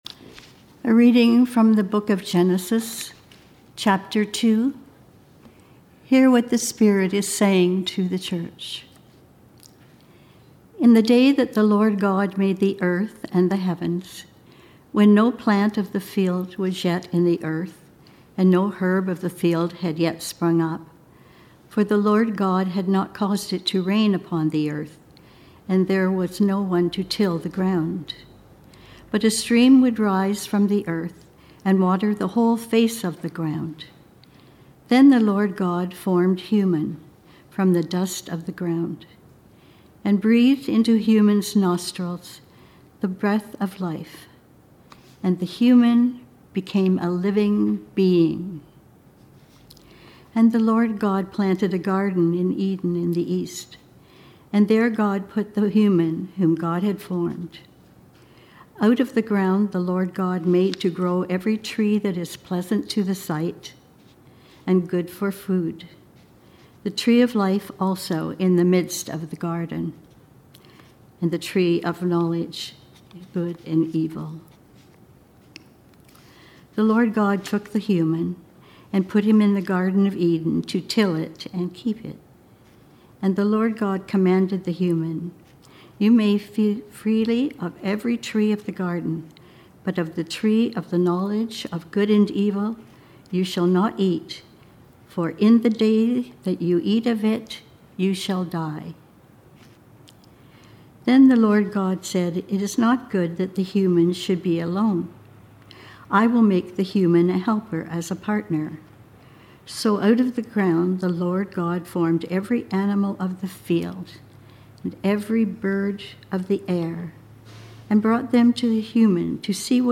Sermons | James Bay United Church